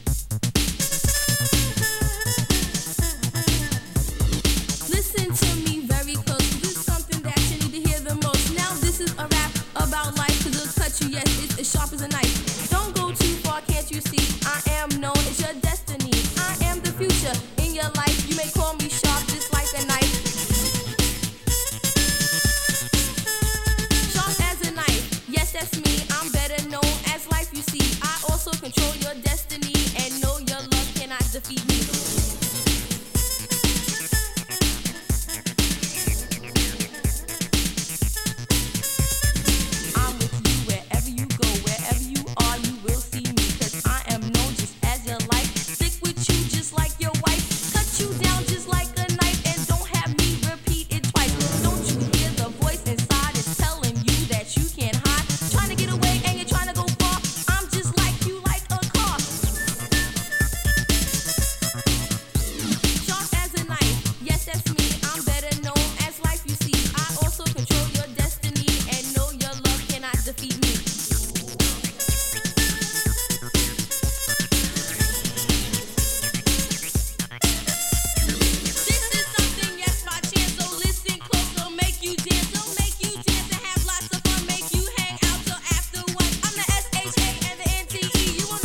Acid Houseビートにホーンが絡むトラックも素晴らしい。
Techno / House 80's~Early 90's レコード